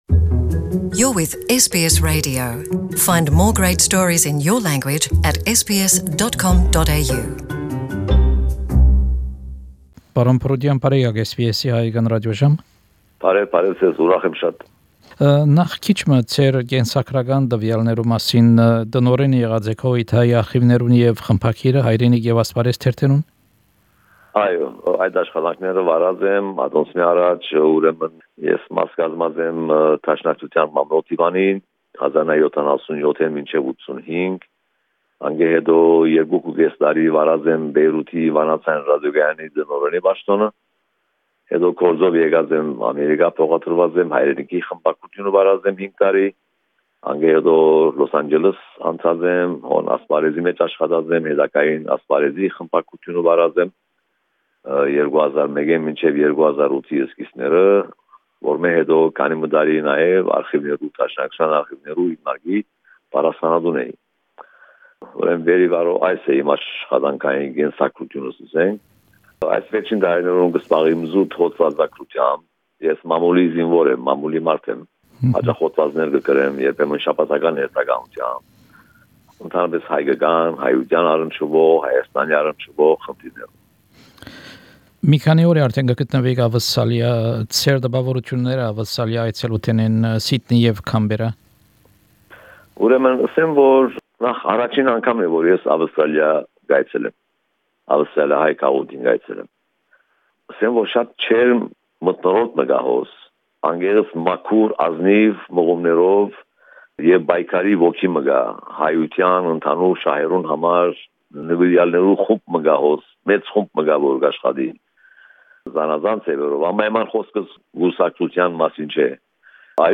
An interview (in Armenian)